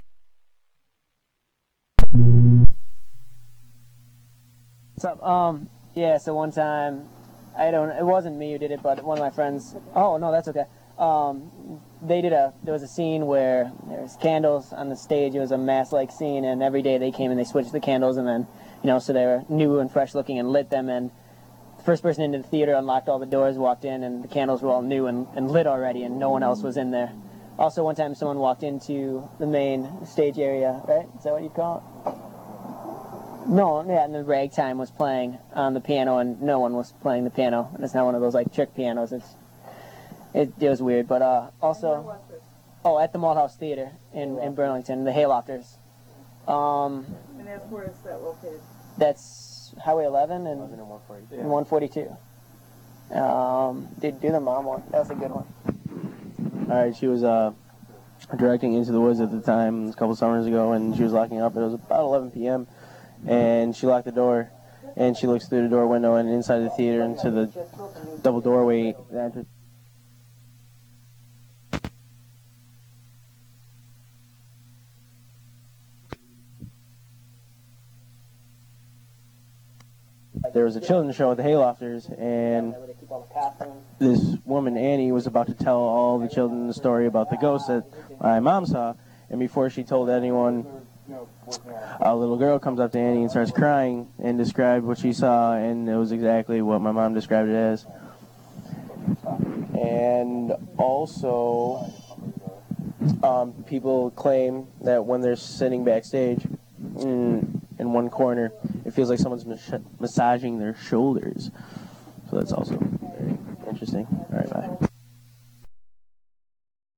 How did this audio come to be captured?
Just let it keep running and the voice will again pick up . I didn't edit the blank space audio because of the possiblity of an EVP that some of you investigators may be able to pick up here in this silenced segment.